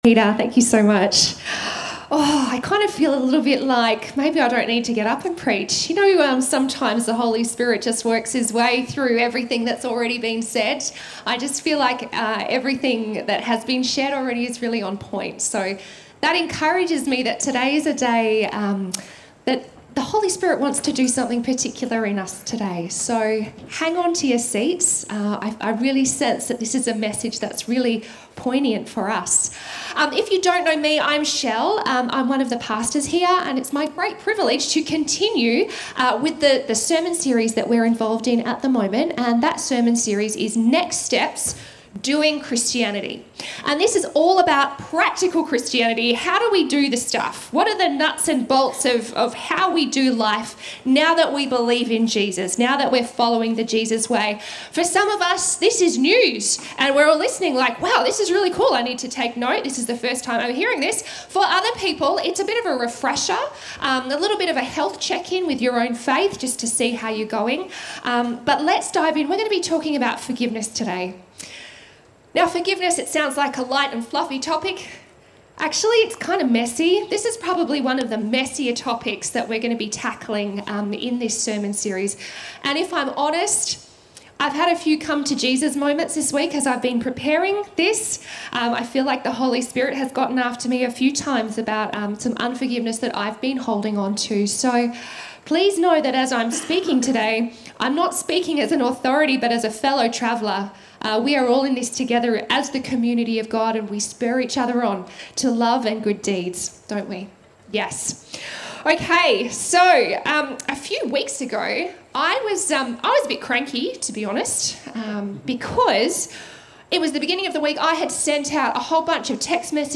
A message from the series "Next Steps."